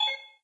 menuclick2.wav